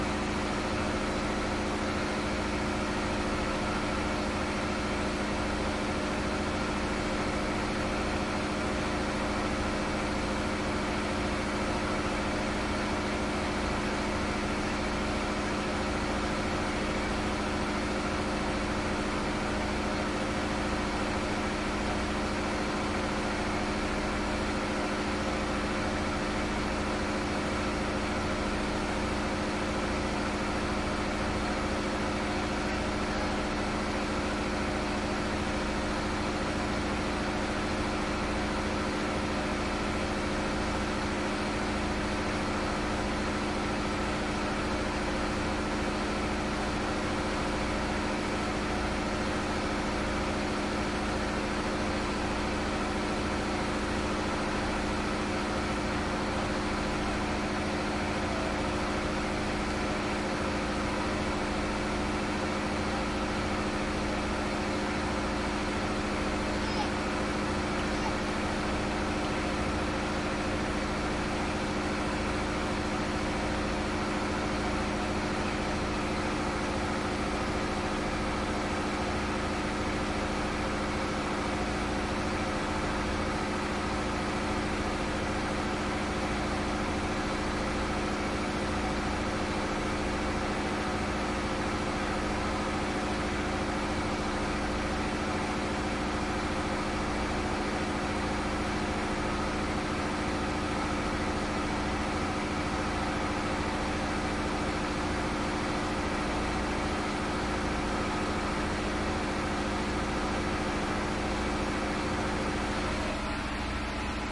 亚马逊 " 船亚马逊渡轮2层柴油驳船上的大声6发动机
描述：船亚马逊渡轮2甲板柴油驳船车载大声引擎
Tag: 驳船 柴油 机载 轮渡